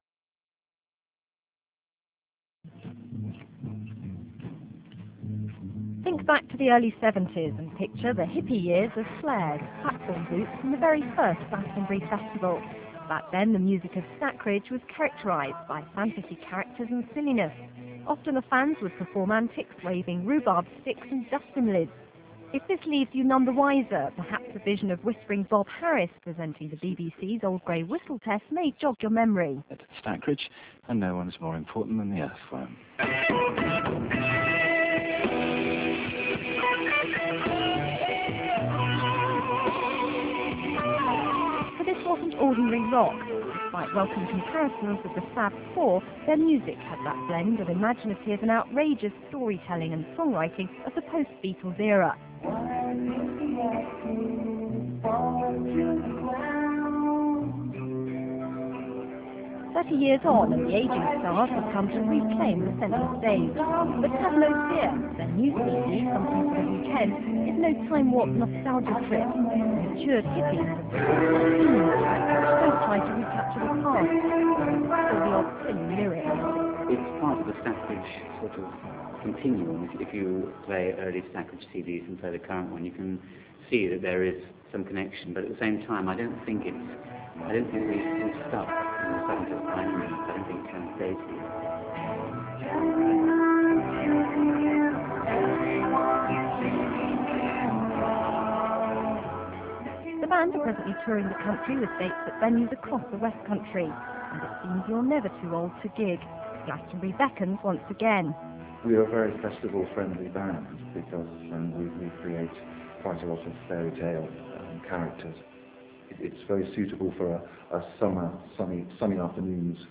The all-action VIDEO clip!
rehearsing